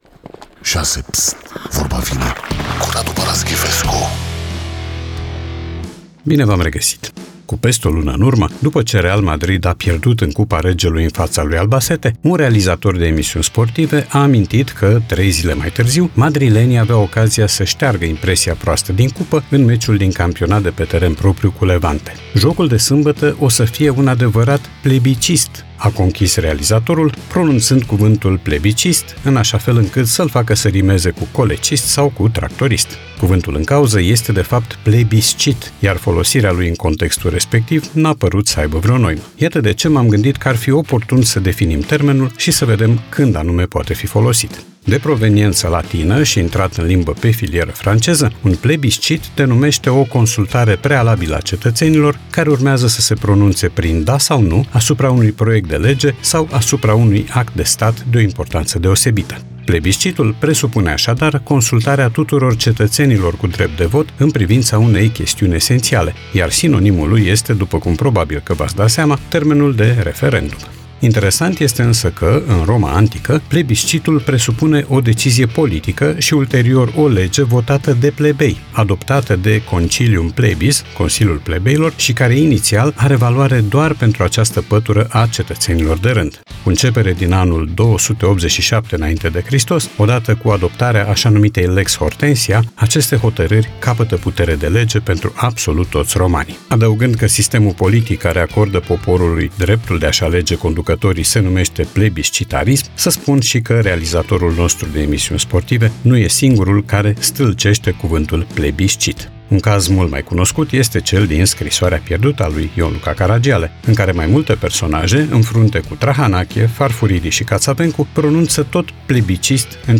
Radu Paraschivescu iti prezinta "Vorba vine", la Rock FM.